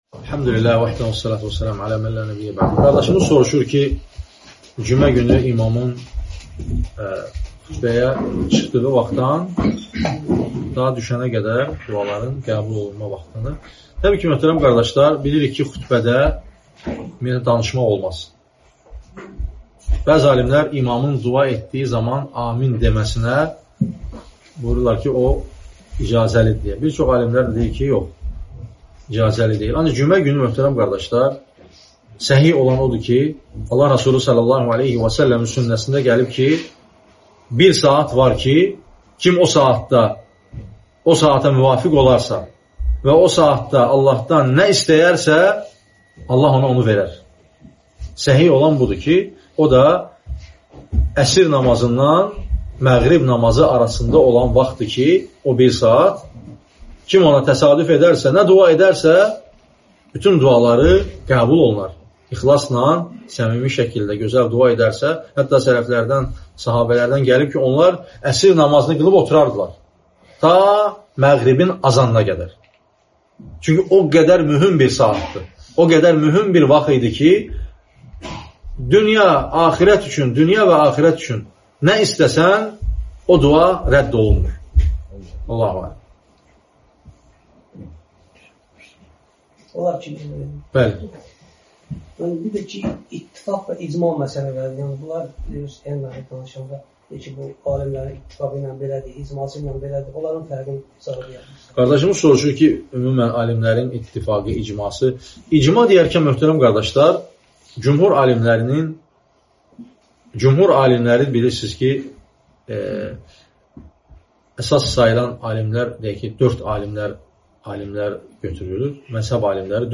Sual-cavab (27.09.2024 Göyçay)